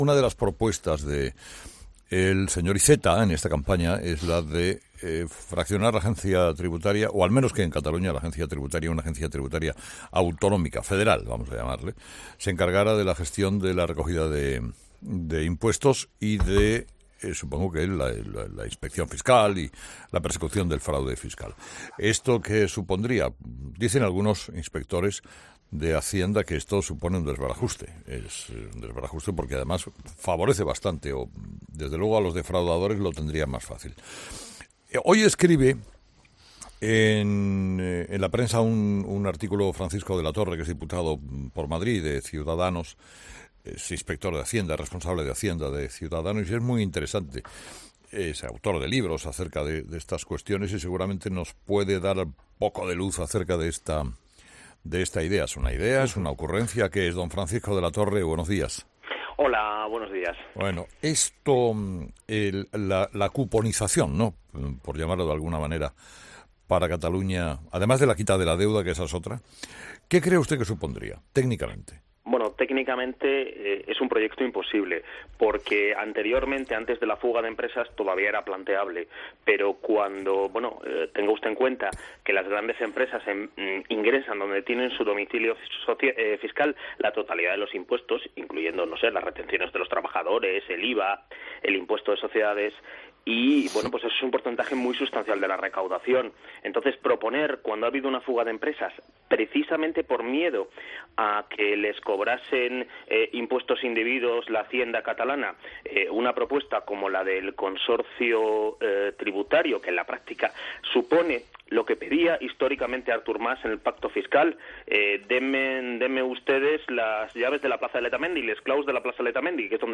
Francisco de la Torre, inspector de Hacienda y diputado de C's por Madrid